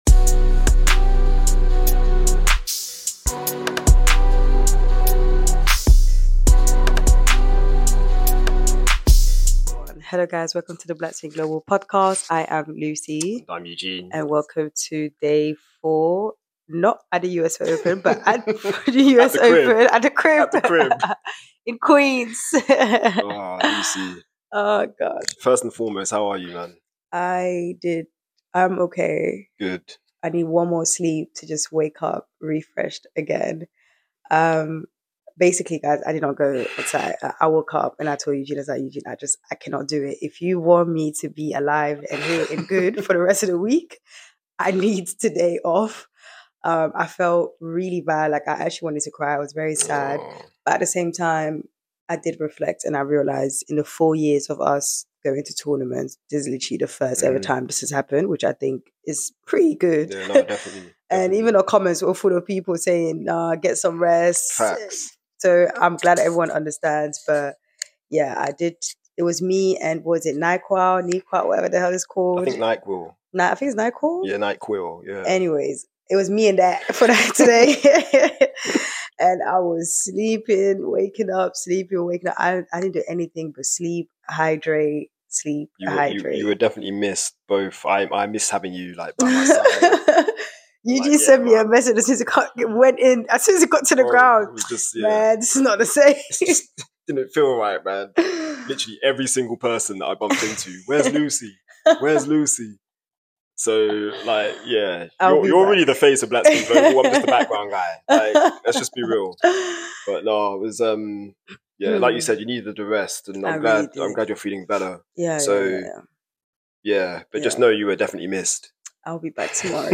Listen out for interview snippets from Townsend, Mirra Andreeva, Tiafoe, Paolini and Shelton.